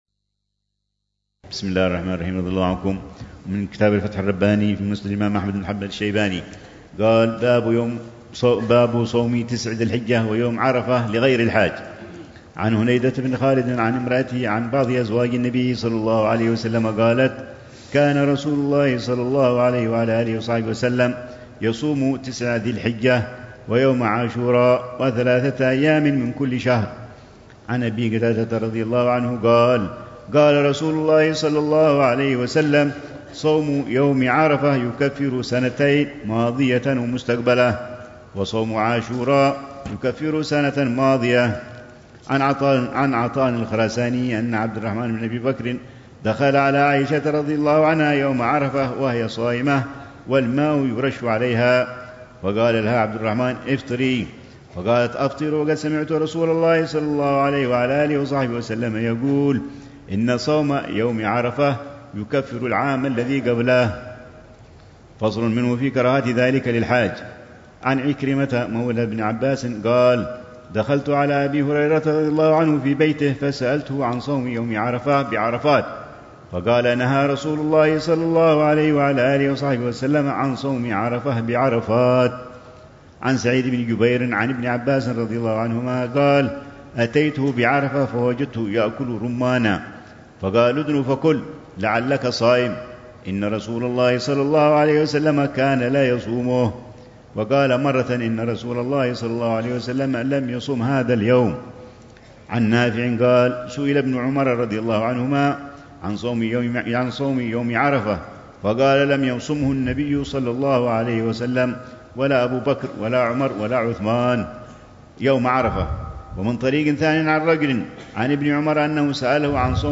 الروحة الرمضانية السادسة بدار المصطفى، وتتضمن شرح الحبيب العلامة عمر بن محمد بن حفيظ لكتاب الصيام من كتاب الفتح الرباني لترتيب مسند الإمام أحم